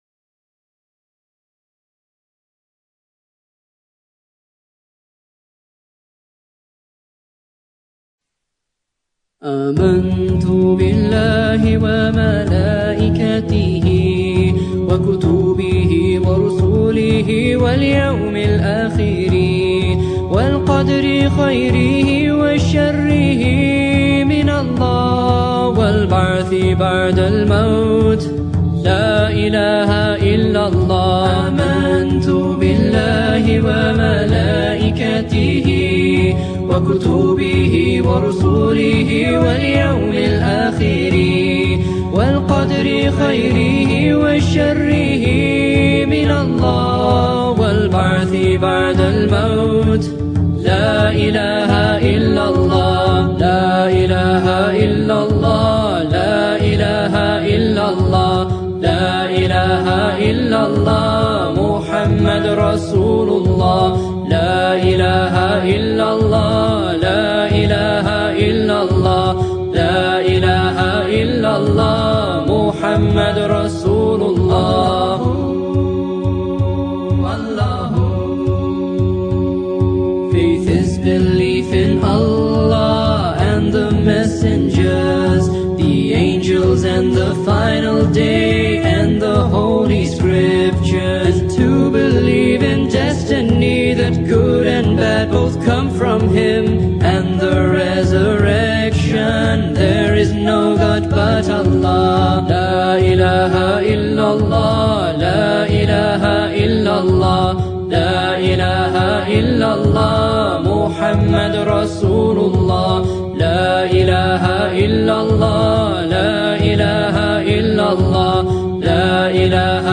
Articles Of Faith – Nasheed – The Deen Show